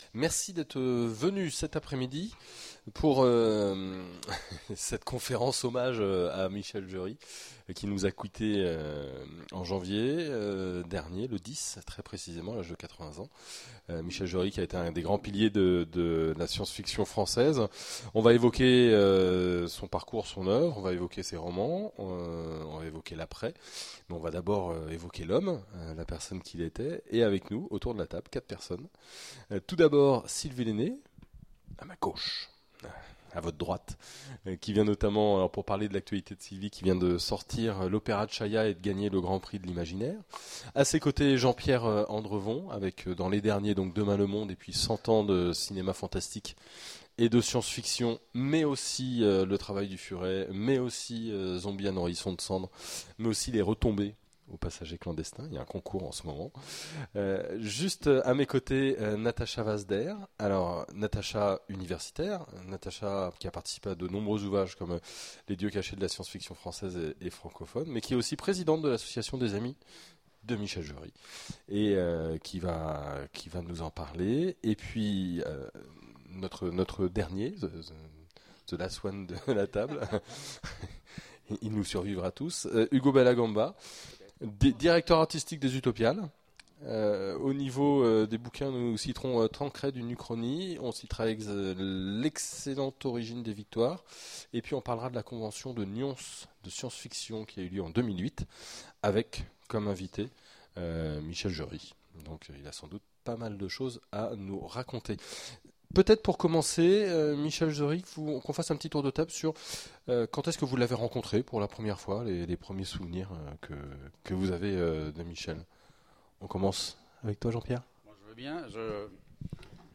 Mots-clés Hommage Conférence Partager cet article